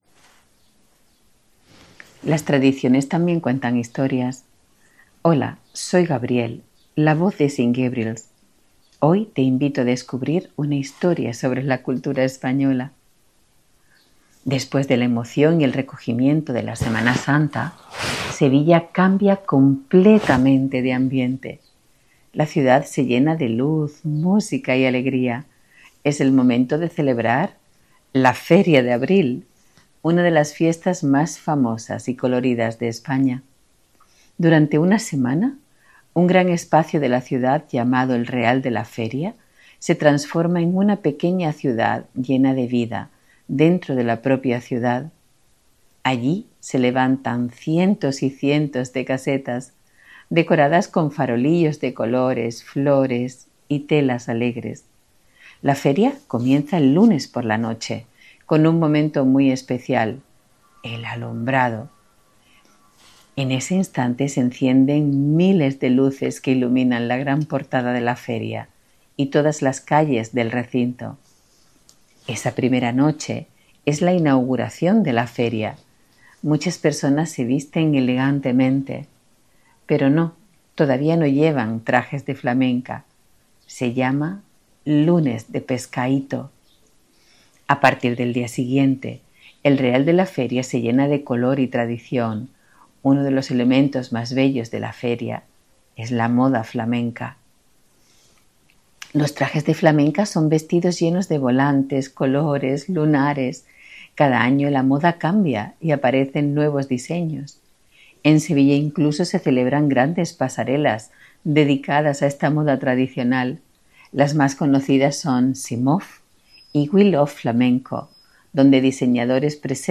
Por eso nace Gabrielle, la voz de St. Gabriel’s: una narradora cálida, cercana y elegante que cada semana nos acompaña con un cuento, una fábula o una historia cultural del mundo hispano.